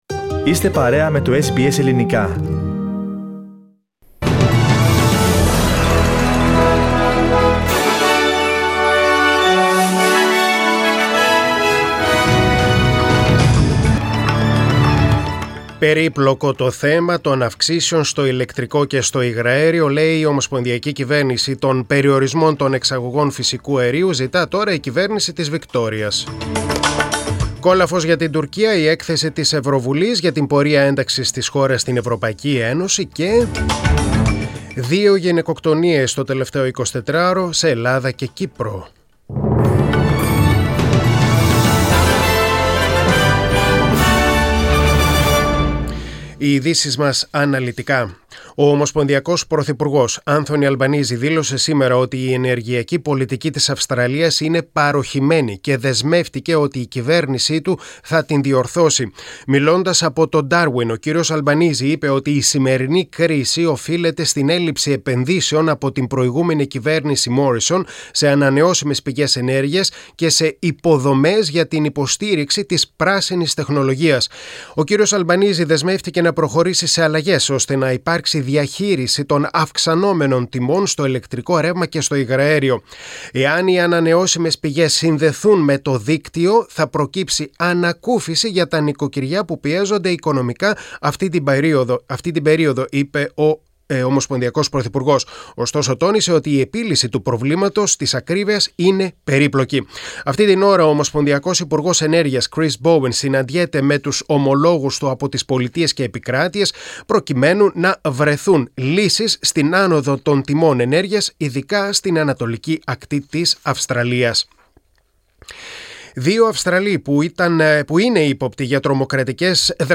Δελτίο Ειδήσεων: Τετάρτη 8.6.2022